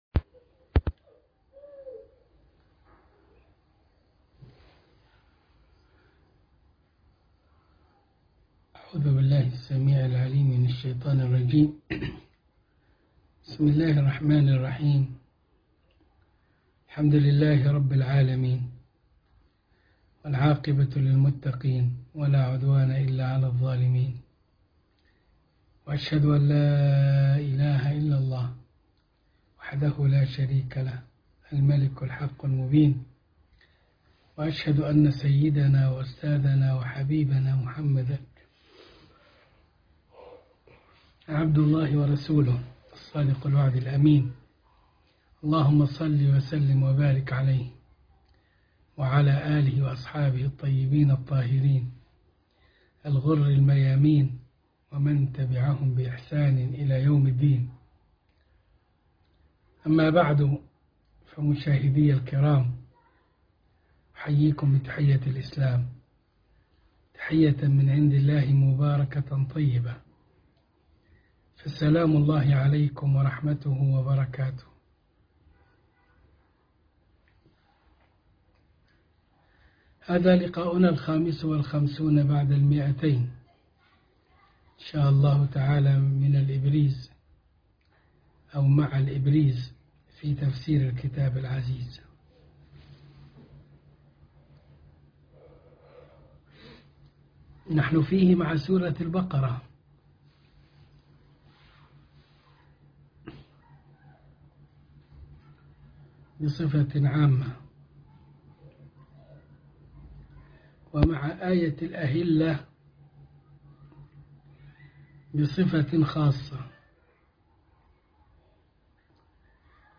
الدرس ٢٥٥ من الإبريز في تفسير الكتاب العزيز سورة البقرة ١٨٩